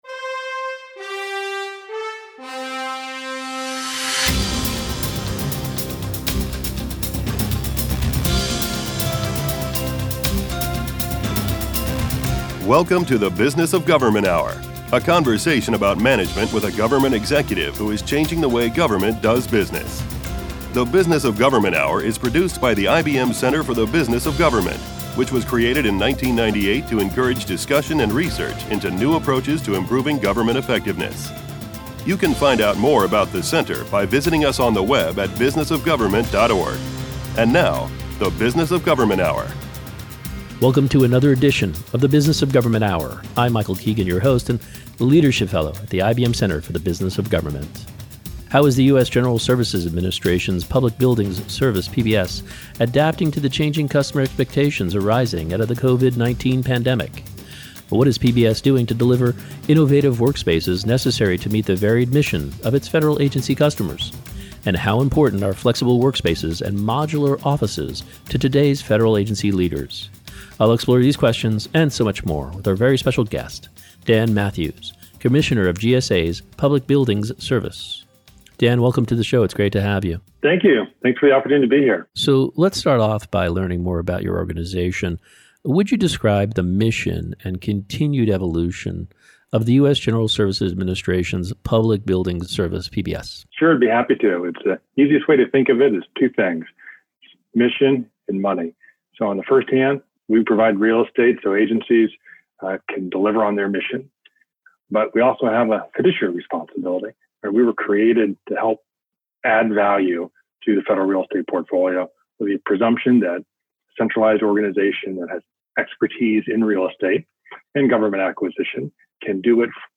Radio Hour Share Podcast TV Hour The Business of Government Hour Stay connected with the IBM Center Download or Email Listen to the Business of Government Hour Anytime, Anywhere Video not available